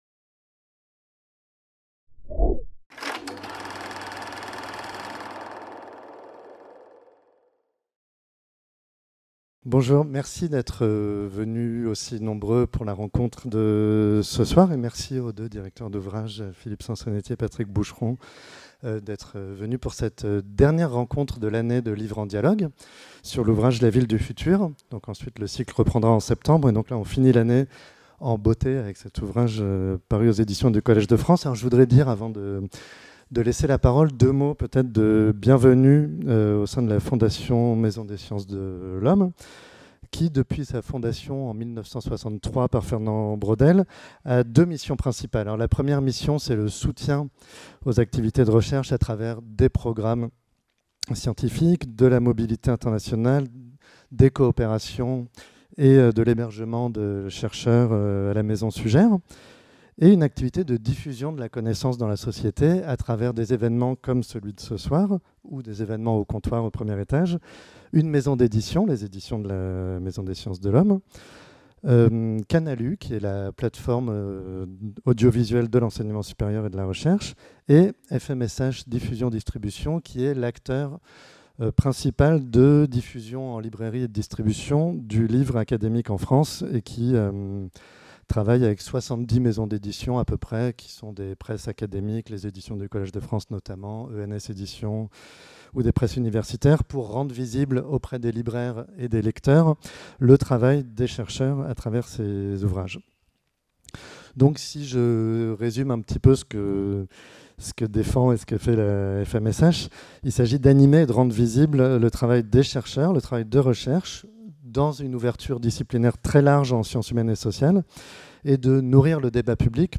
Soirée de présentation de l'ouvrage "La ville du futur" | Canal U